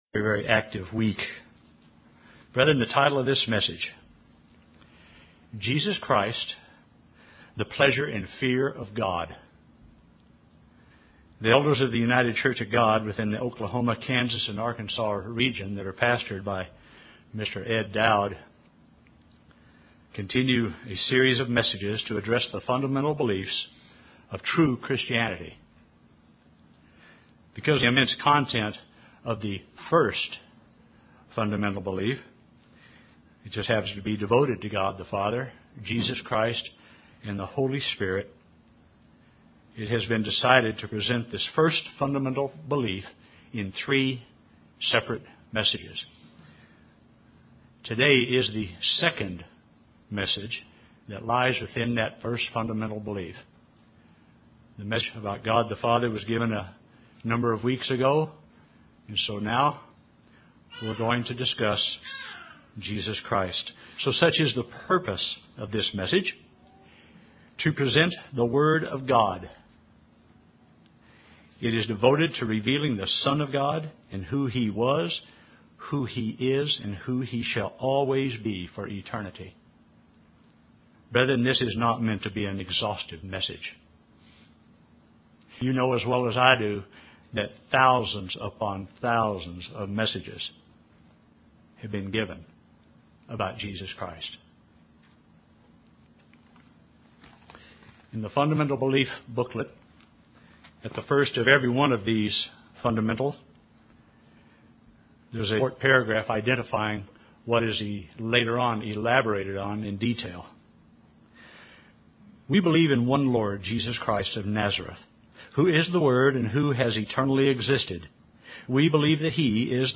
This sermon is second installment of the Fundamental Belief of the Godhead, focusing on Jesus Christ, the Pleasure and Fear of God.
Given in Oklahoma City, OK